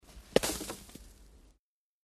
SnowballHit PE570601
Snowball hit